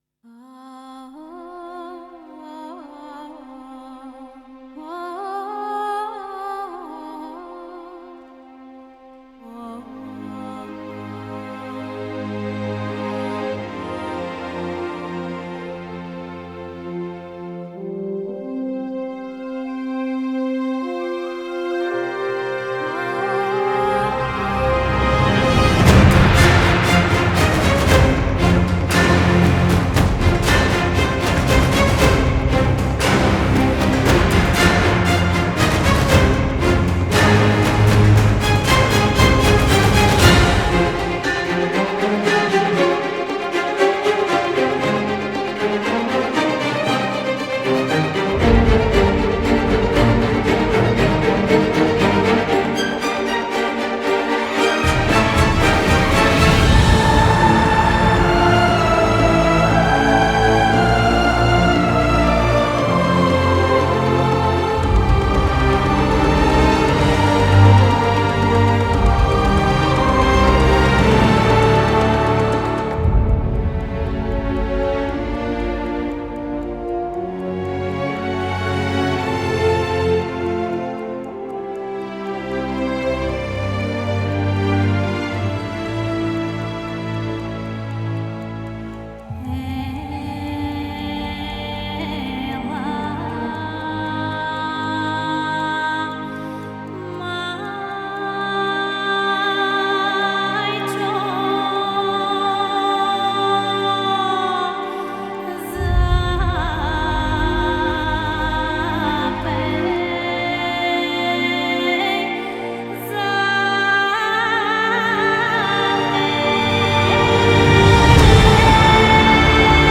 موسیقی بیکلام
موسیقی حماسی